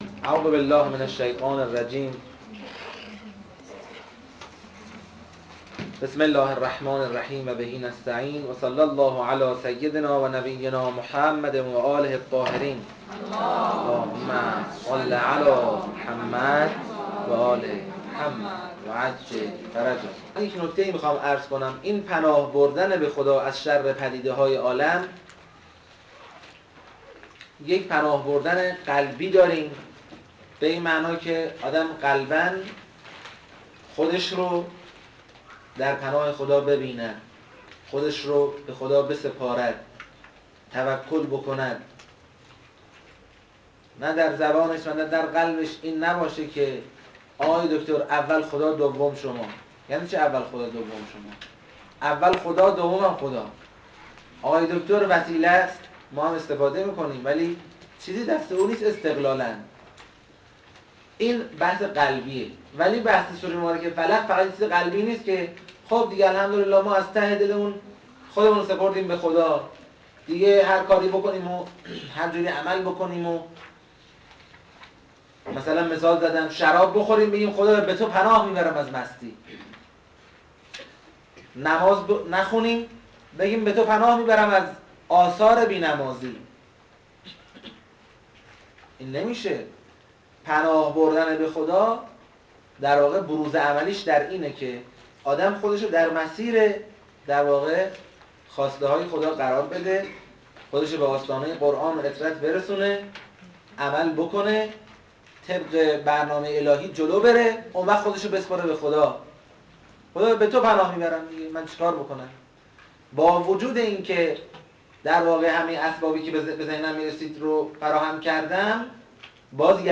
این مجموعه توسط واحد رسانه موسسه تدبر از کلاس ها و سخنرانی های ایشان تهیه شده است.